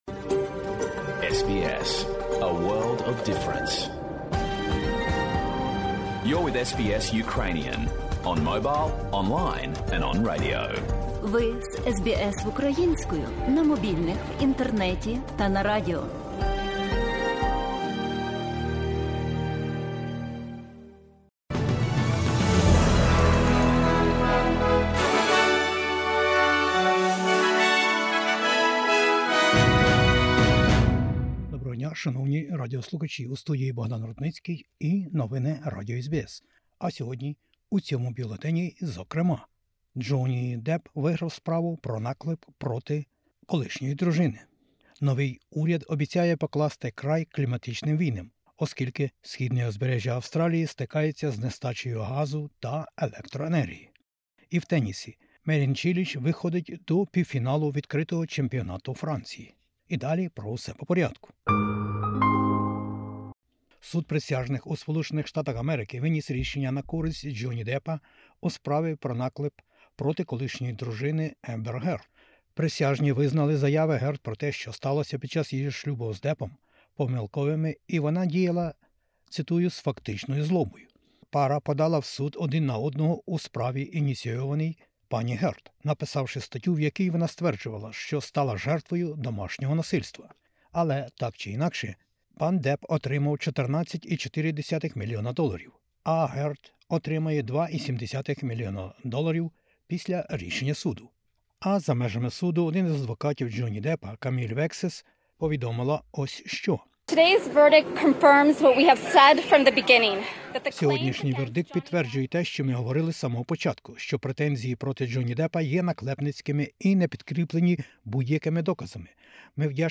Бюлетень SBS новин українською мовою. Новий уряд: питання зміни клімату, зовнішня політика щодо реґіонів та енерґоресурси. Судова справа про наклеп має переможця. США про нову допомогу Україні та про винуватця війни.